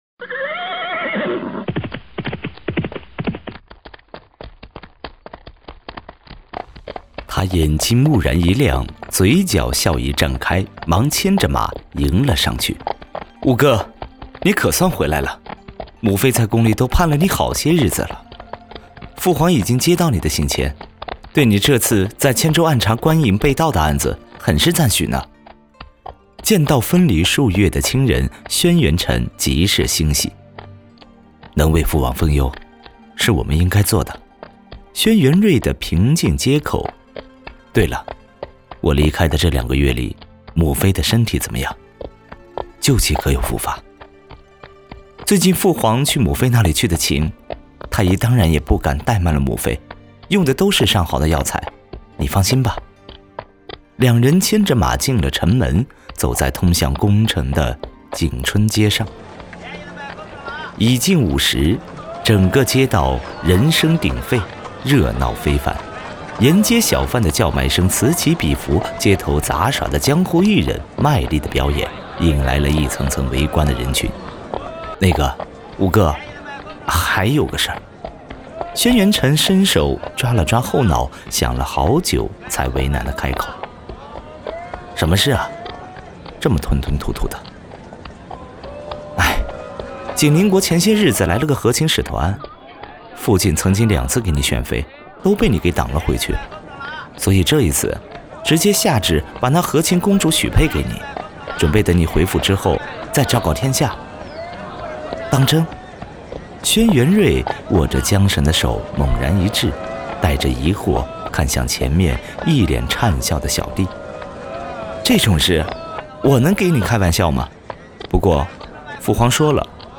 • 2央视男声2号
电视剧解说 磁性大气